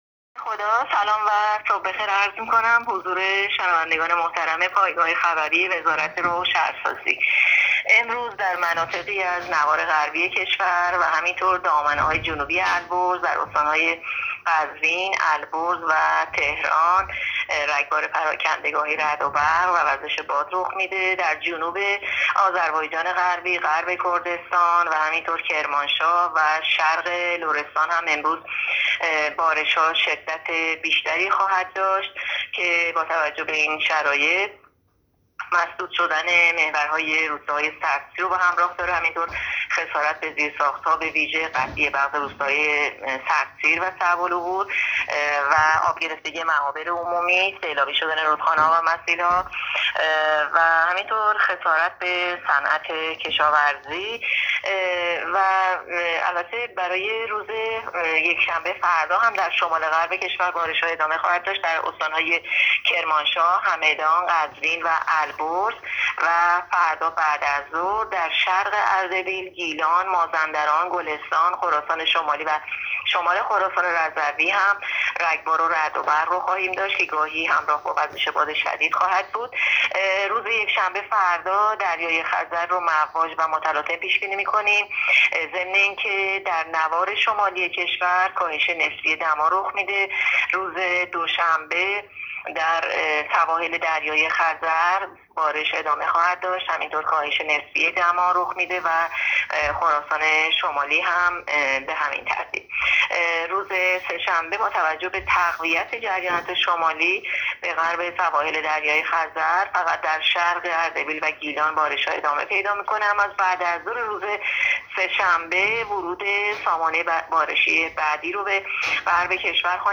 گزارش رادیو اینترنتی پایگاه خبری از آخرین وضعیت آب‌وهوای ۵ آذر؛